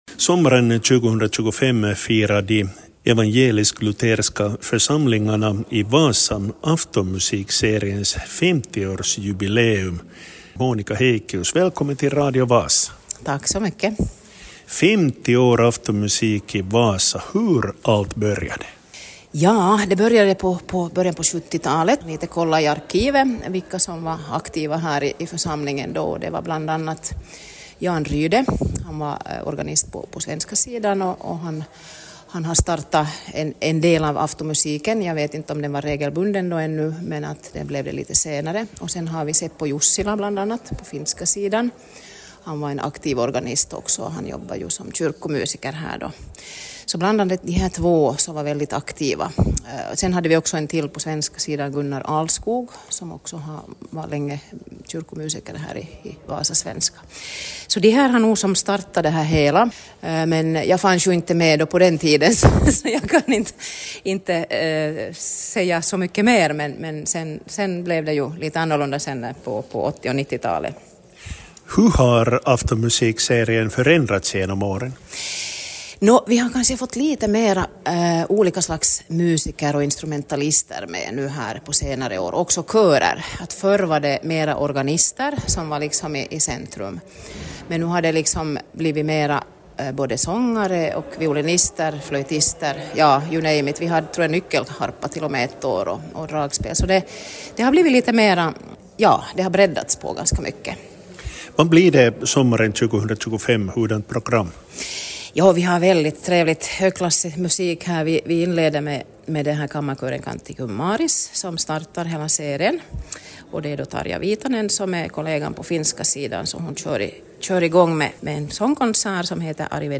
intervjuas